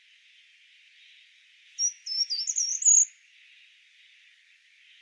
2. Cooper’s Hawk (Accipiter cooperii)
• Call: Sharp cackling sounds: